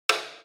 Dong Sound
cartoon